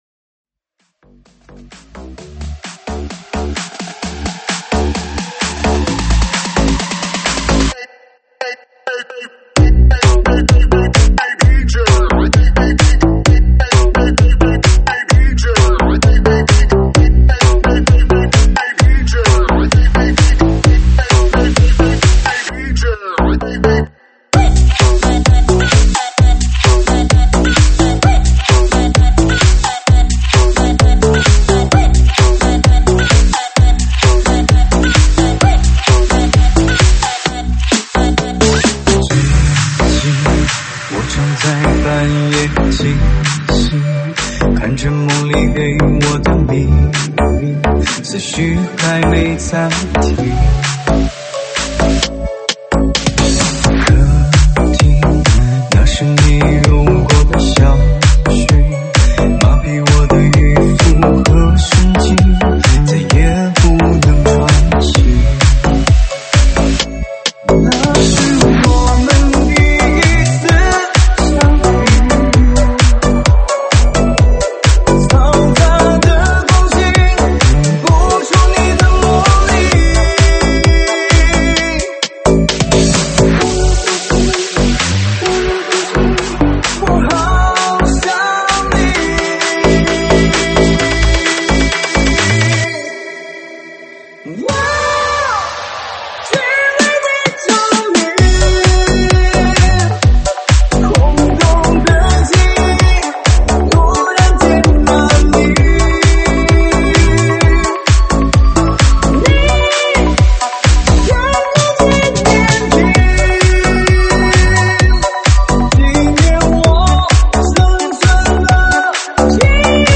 [现场串烧]
舞曲类别：现场串烧